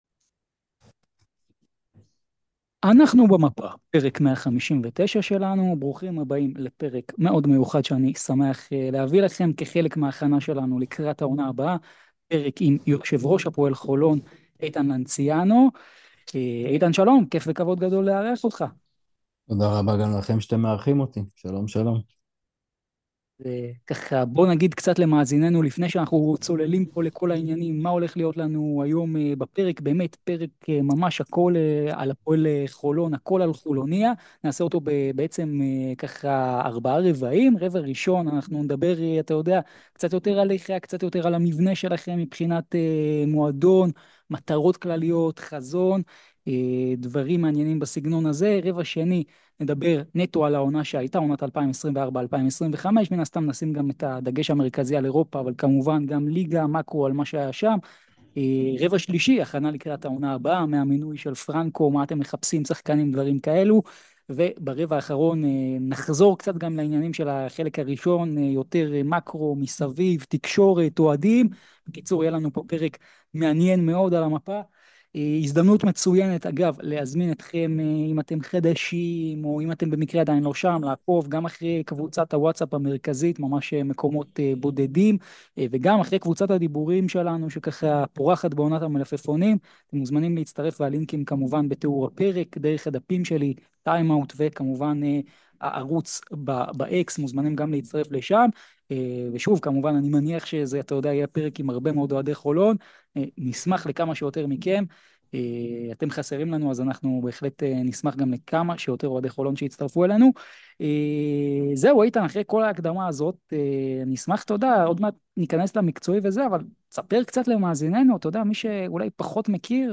ובתפריט היום מחכה לכם ראיון בלעדי ומיוחד